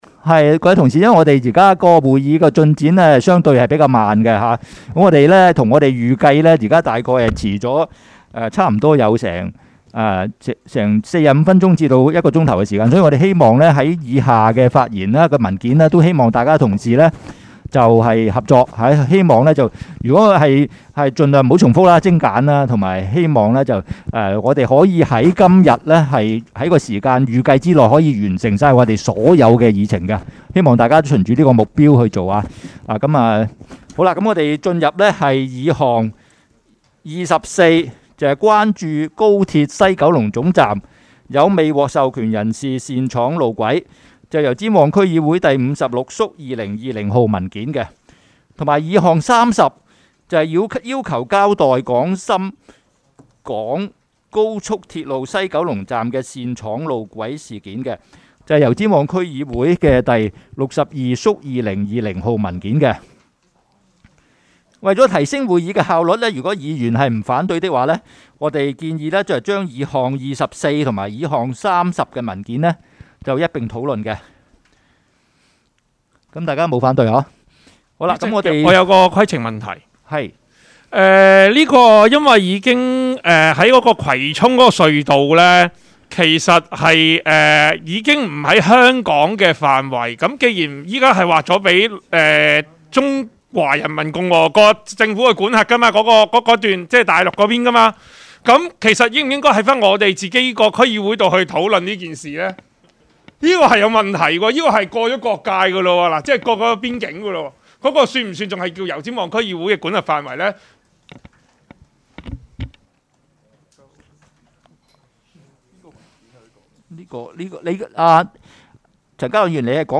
区议会大会的录音记录